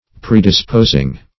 Predispose \Pre`dis*pose"\, v. t. [imp. & p. p. Predisposed;